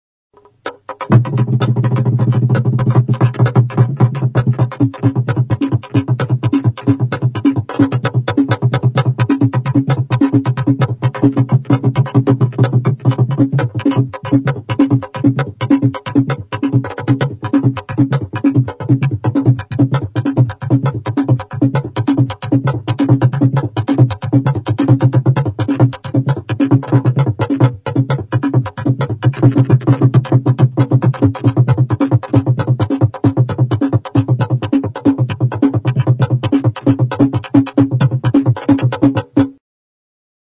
Idiophones -> Struck -> Directly
TAMBOURS DE BOIS A FENTE. Central African Republic.
Animalia baten itxura estilizatua hartzen duen zurezko danborra da (burua, isatsa, lau hankak).